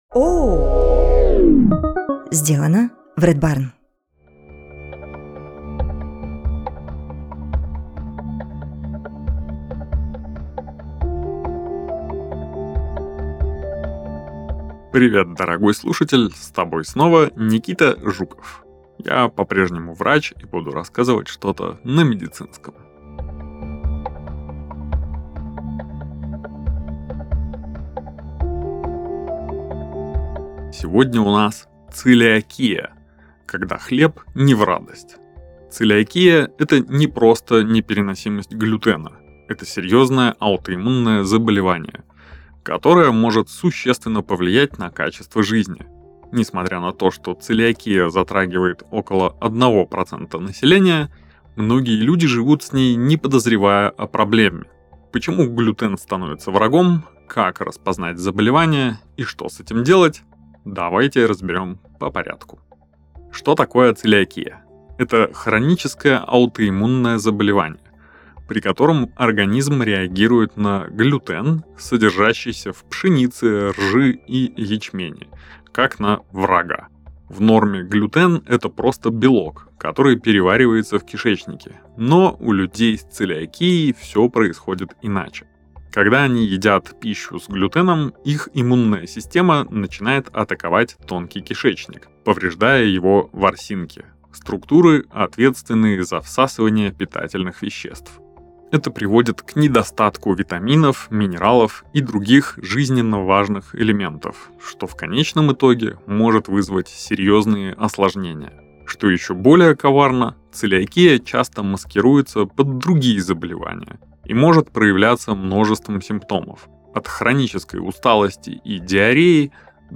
Научпоп-подкаст о медицине.
с юмором рассказывает о существующих и уже забытых болезнях и развенчивает мифы.